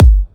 edm-kick-62.wav